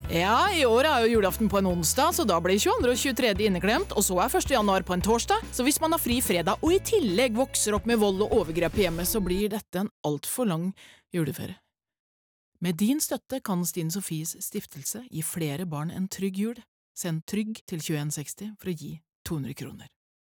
Med et tydelig overraskelseselement tas publikum fra juleidyll til en langt vanskeligere virkelighet for dem som har det tøffest. Overgangen fra det lystige til det vonde er effektivt og godt balansert, støttet av en svært sterk voice.